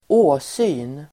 Uttal: [²'å:sy:n]